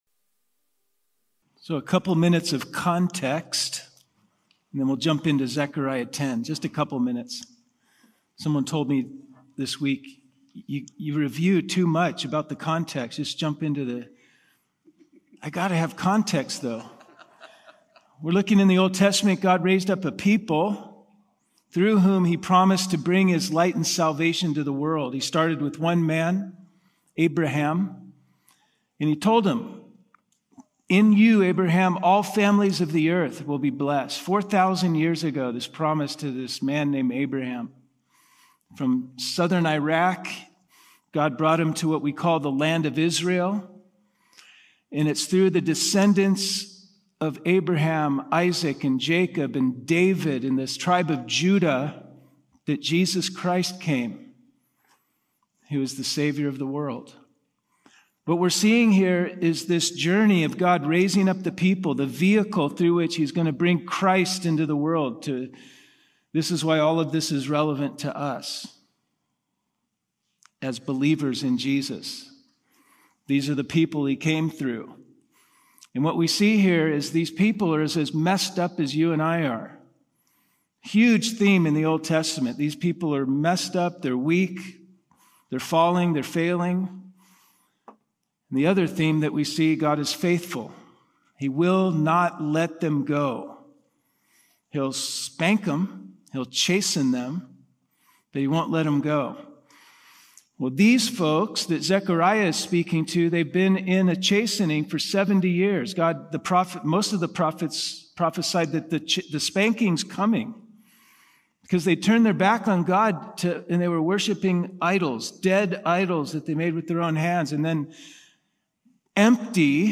Packinghouse’s Wednesday night worship service from March 5, 2025.